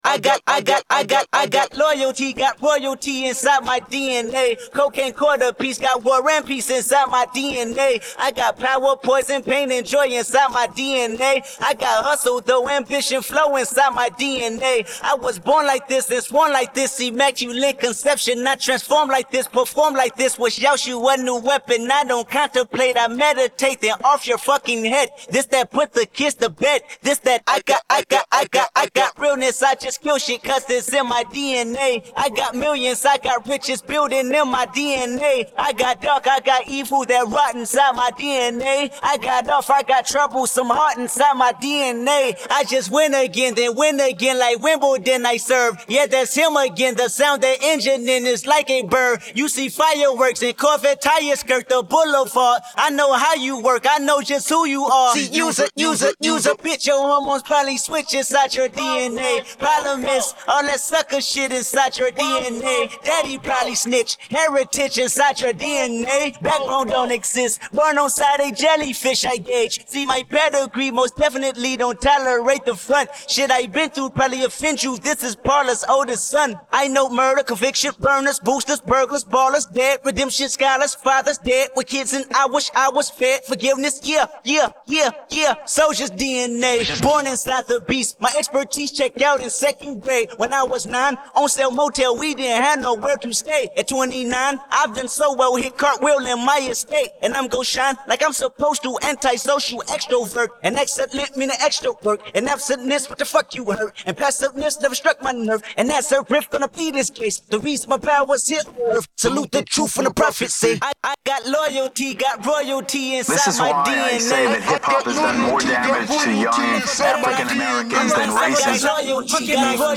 Голосова частина